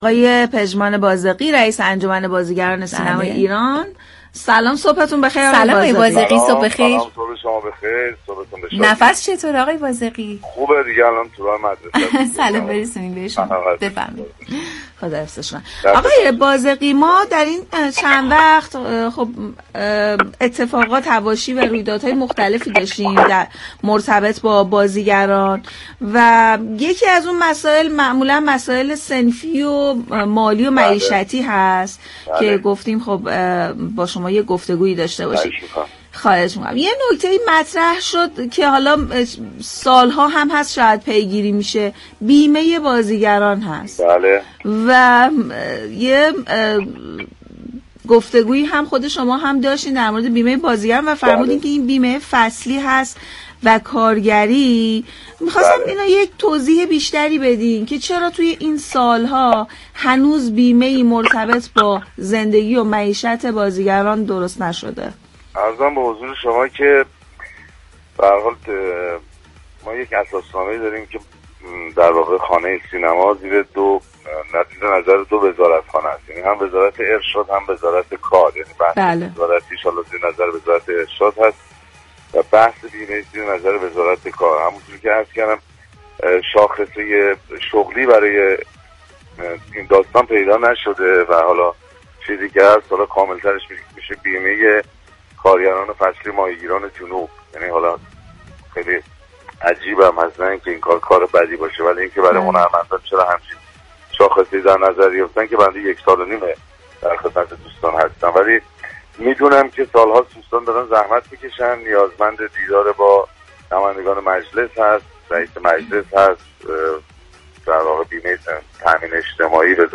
به گزارش پایگاه اطلاع رسانی رادیو تهران، پژمان بازغی رئیس انجمن بازیگران سینمای ایران در گفت و گو با «بام تهران» اظهار داشت: واقعیت این است كه در وزارت كار در شناسه ملی خانه سینما ما بازیگران به‌عنوان كارگران فصلی ماهیگیران جنوب بیمه می‌شویم؛ یعنی ما بازیگران را به‌عنوان كارگران فصلی تعریف كرده‌اند.